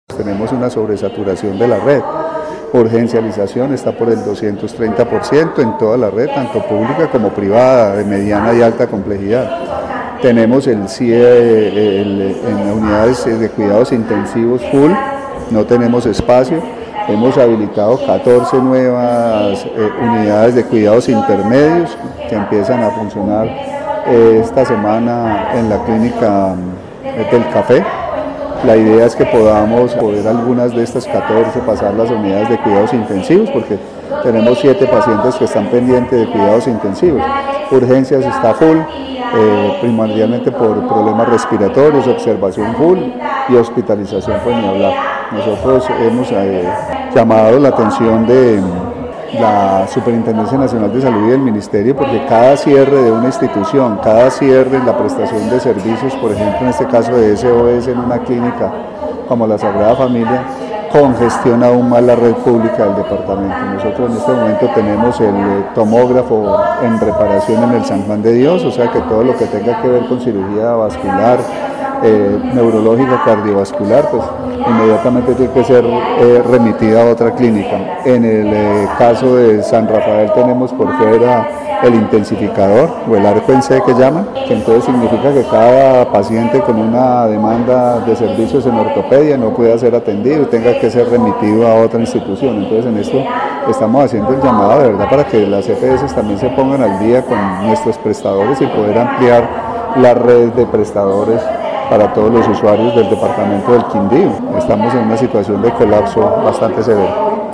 Así lo dijo el secretario de Salud del departamento, Carlos Alberto Gómez
Secretario de Salud del Quindío